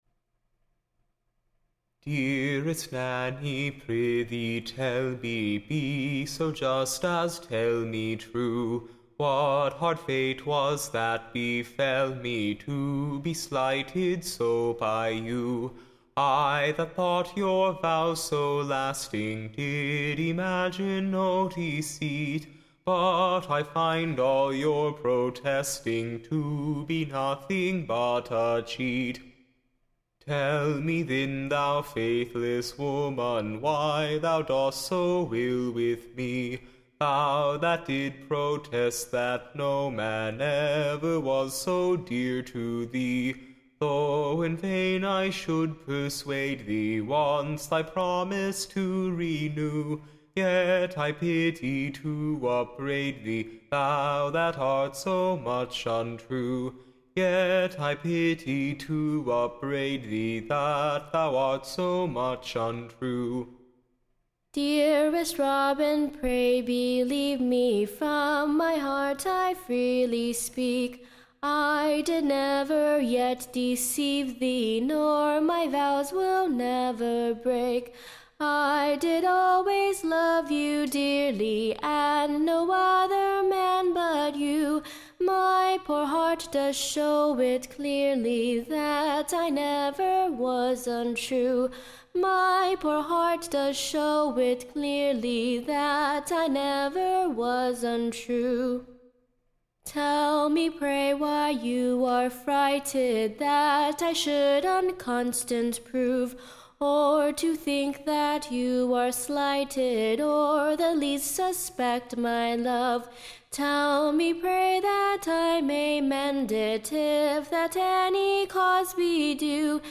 Recording Information Ballad Title Doubtful ROBIN; / Or, Constant NANNY. / A New BALLAD.